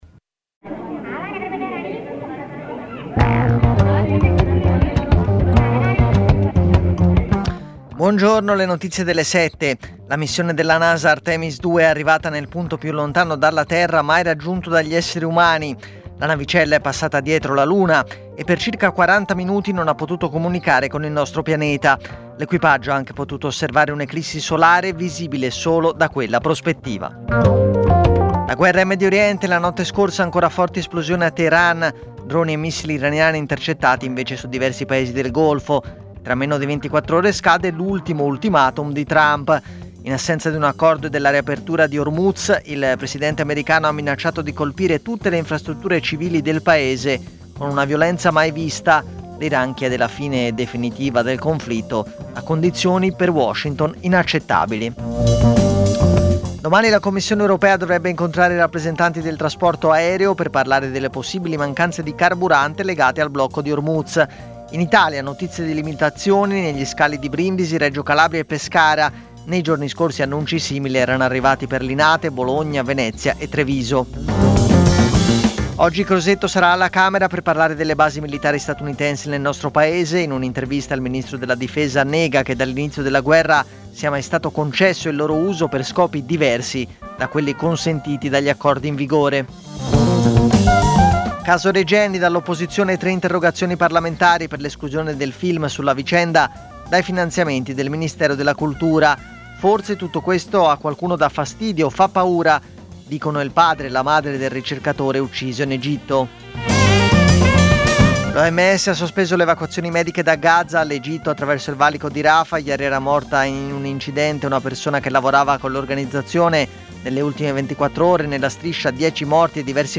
Edizione breve del notiziario di Radio Popolare.